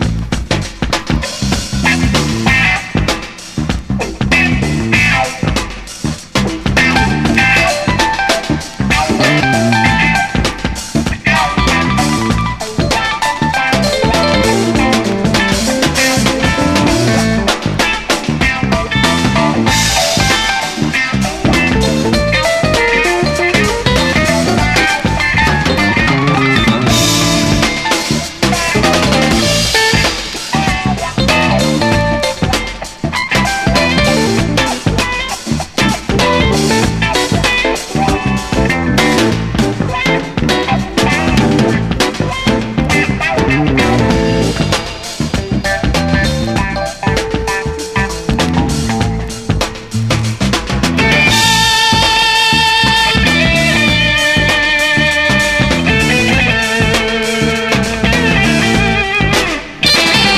ROCK / 60'S / PROGRESSIVE ROCK / SOFT ROCK